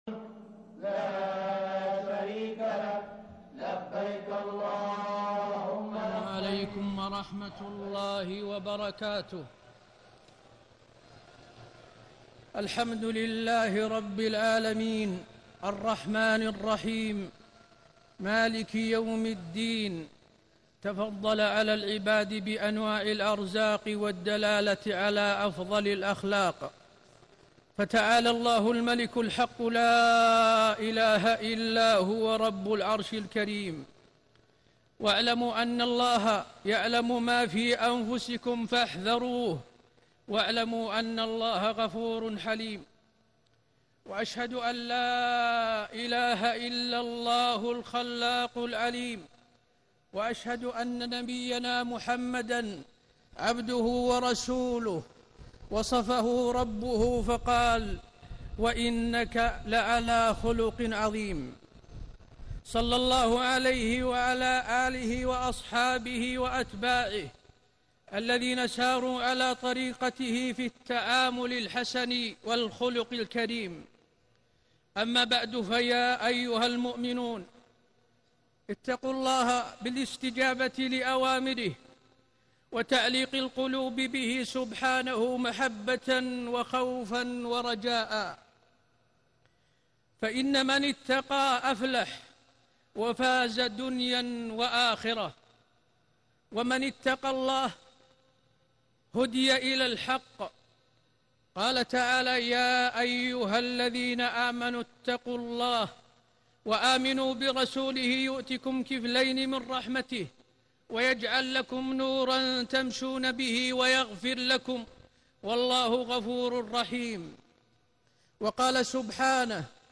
خطبة يوم عرفة 1439هـ للشيخ حسين آل الشيخ > خطب عرفة > المزيد - تلاوات الحرمين